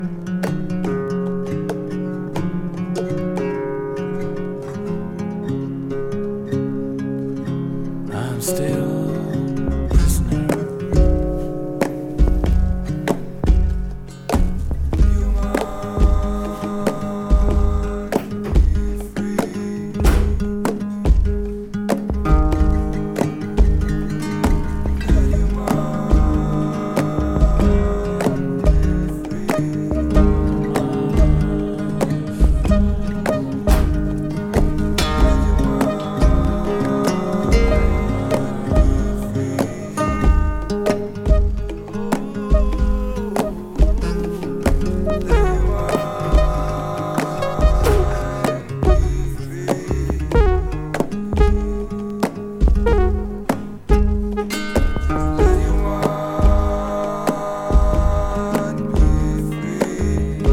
カラフルなTech Houseトラックや、Indie Rockテイストの曲など収録♪Label